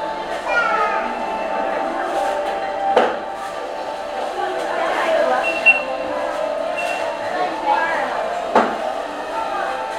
Scene_Noise_Data